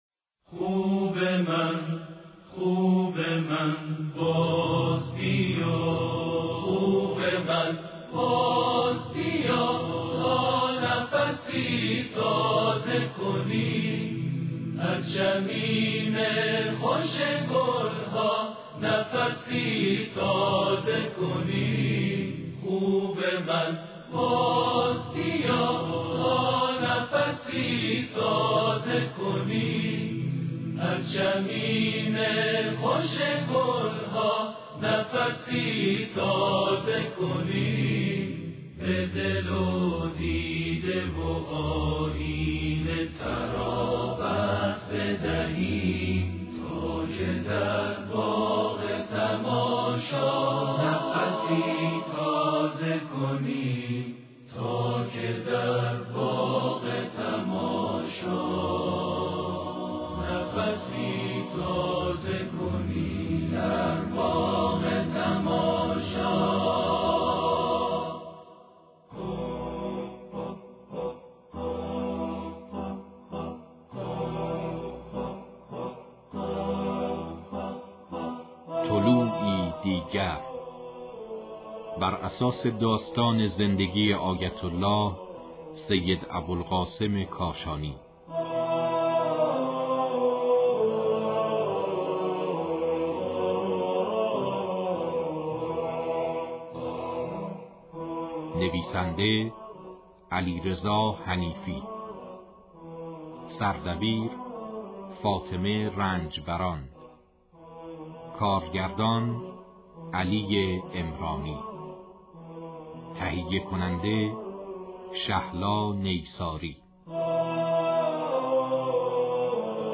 نمایش رادیویی - سید ابوالقاسم کاشانی